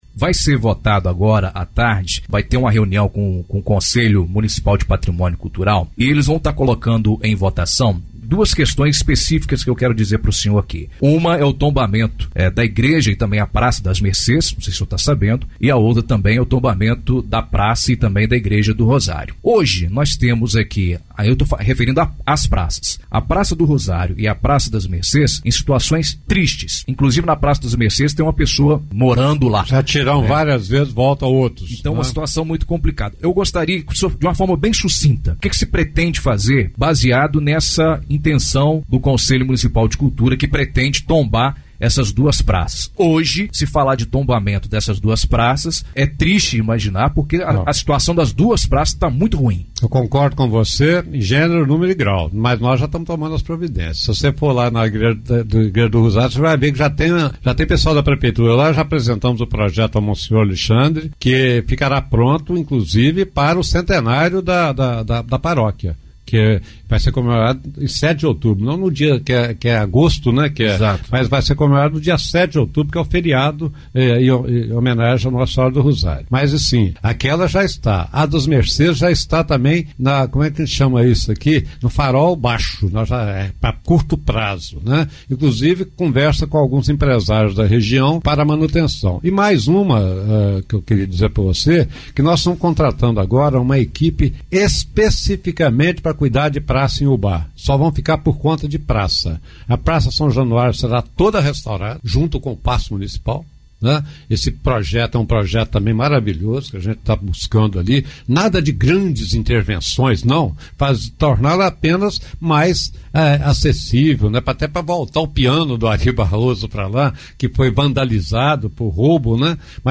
Entrevista exibida na Rádio Ubaense FM 104,1